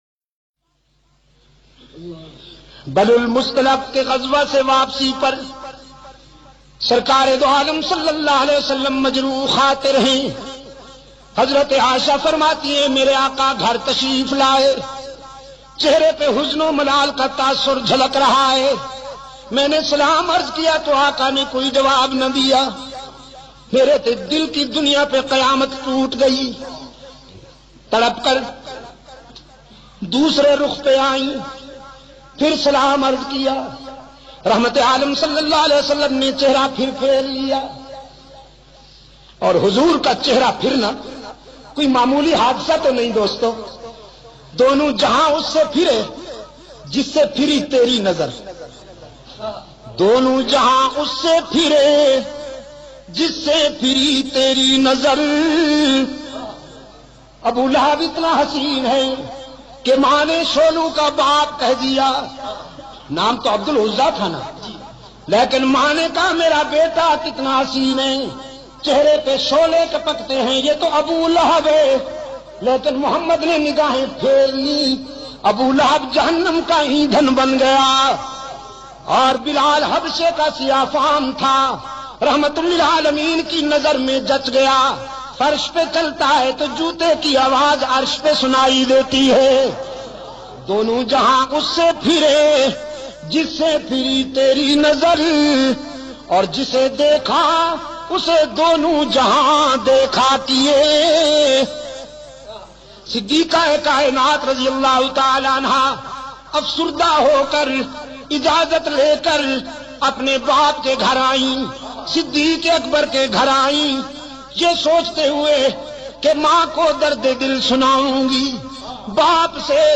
Beautifull bayan.mp3